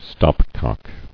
[stop·cock]